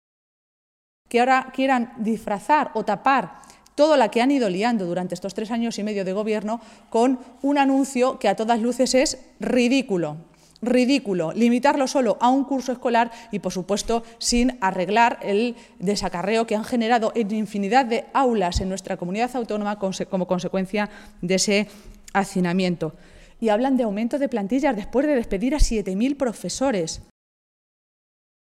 Maestres e pronunciaba de esta manera esta mañana, en una comparecencia ante los medios de comunicación, en Toledo, en la que se refería al hecho de que Castilla-La Mancha afronta el arranque del nuevo curso escolar como la única región que va a aplicar, íntegramente y desde el principio, esa misma Ley Wert.